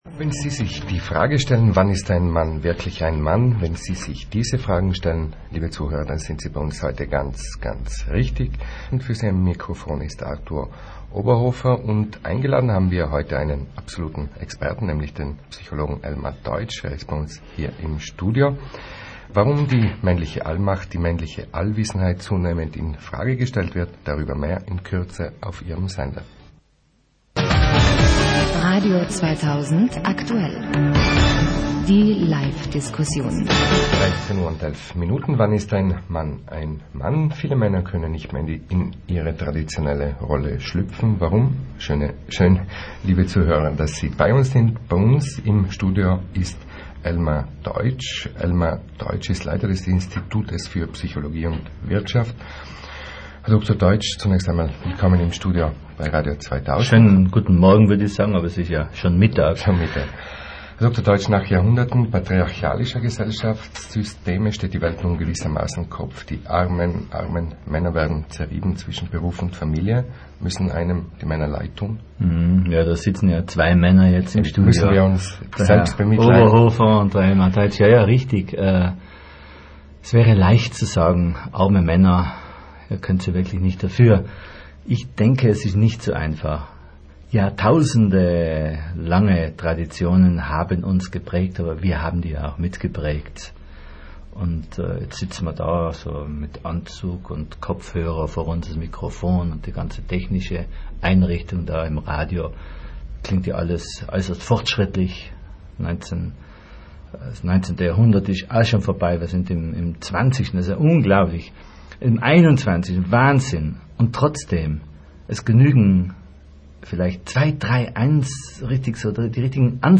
Holen Sie sich hier Ausschnitte aus der Sendung: Komprimierte WEB-Version, Sprache und Musik, 40 Minuten, 6,6MB >>